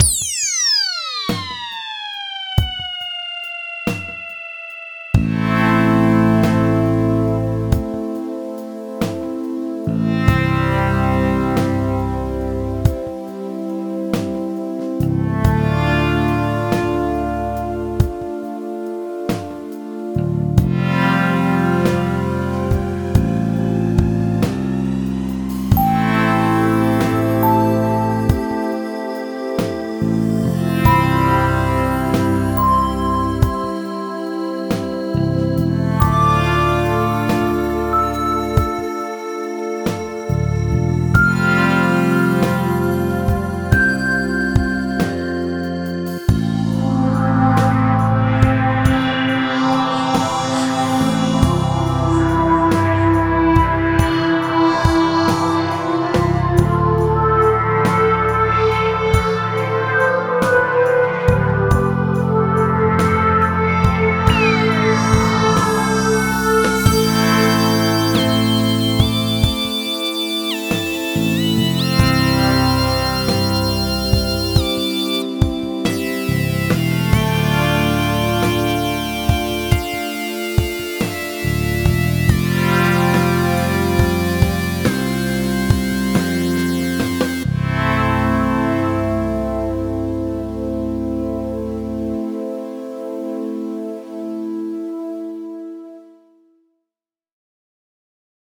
Home > Music > Ambient > Smooth > Dreamy > Mysterious